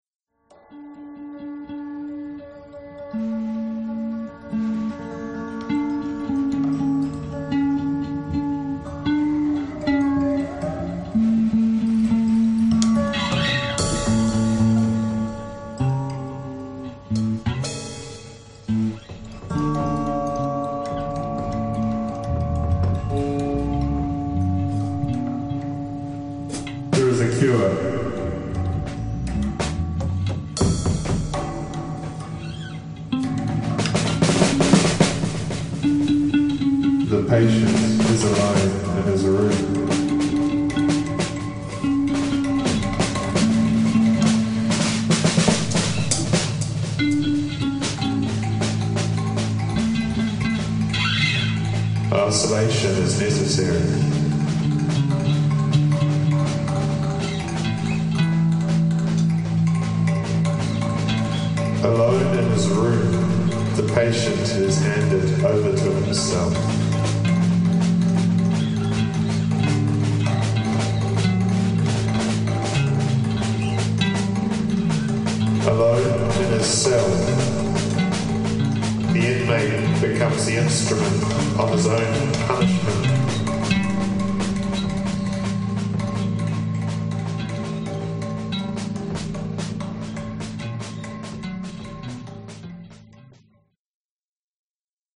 To the Moon in Seven Easy Steps (recorded in Dunedin